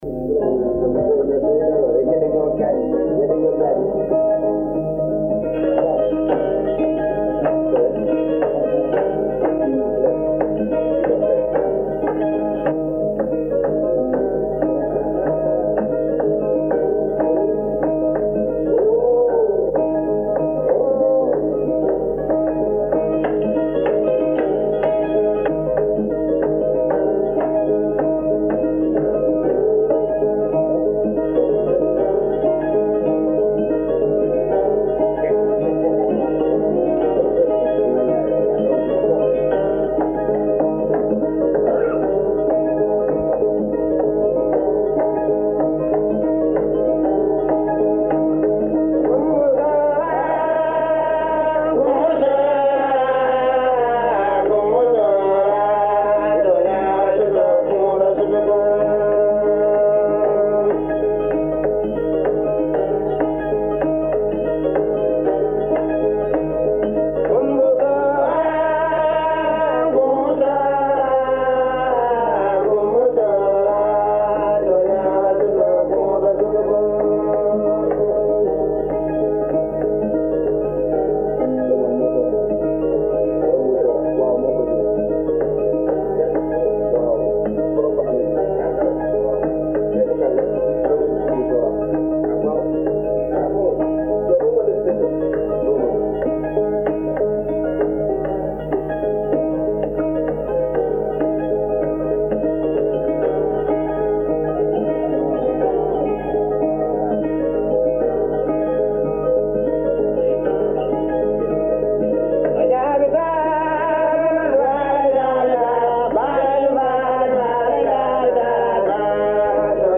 La cola de l'amitié n'est jamais petite : Conte malien · OmekaS By DataCup · Omekas - Mali